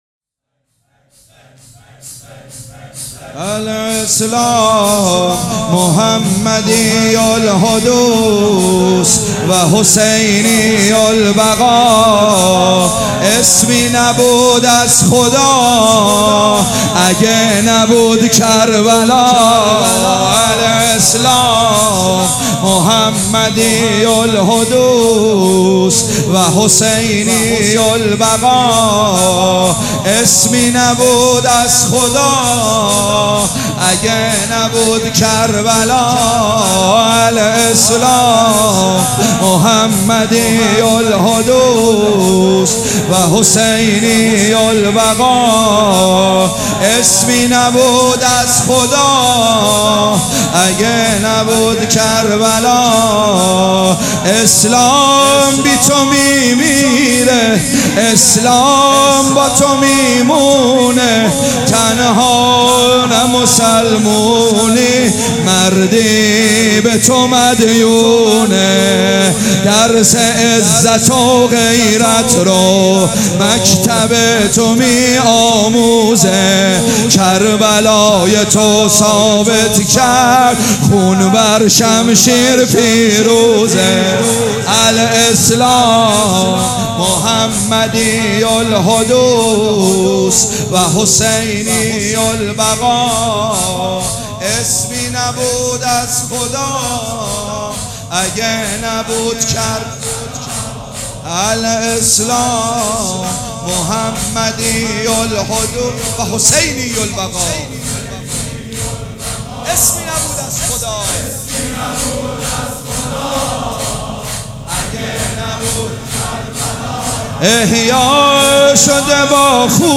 شور
مداح
مراسم عزاداری شب دوم